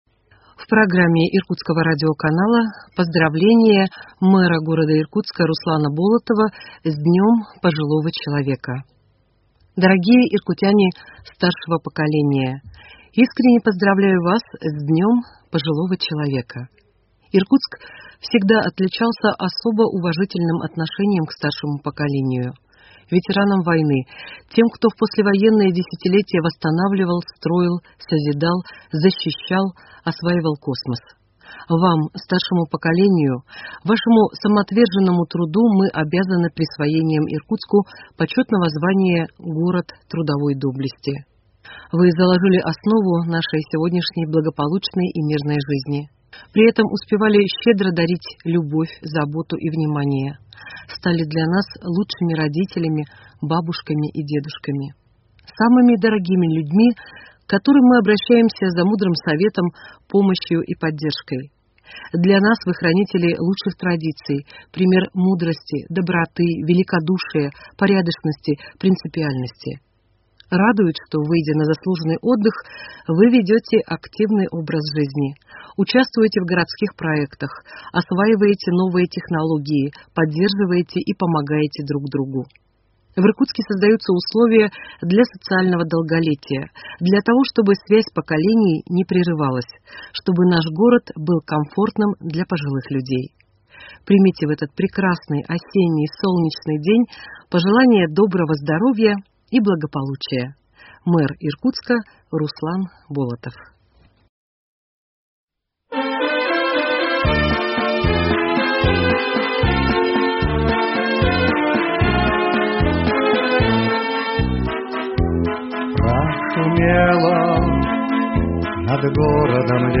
Поздравление мэра г. Иркутска Р.Н. Болотова
интервью по телефону